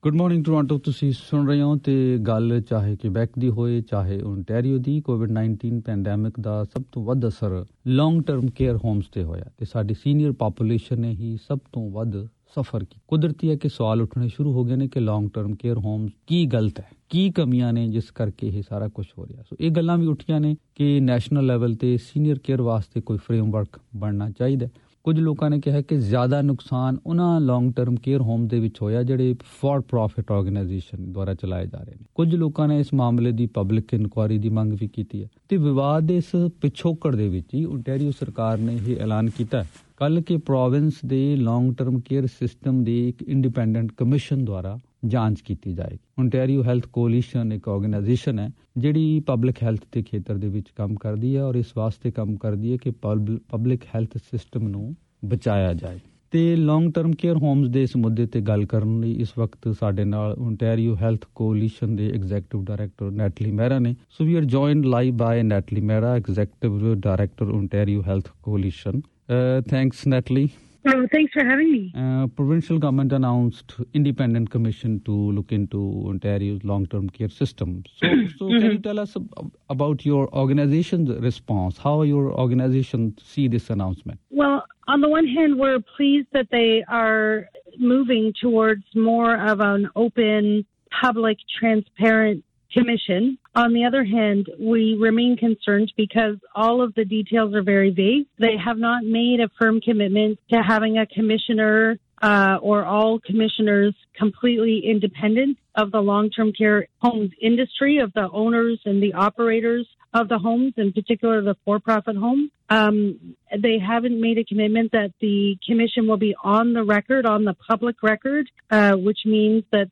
COVID-19: Inquiry Ordered Into Long-Term Care (Interview)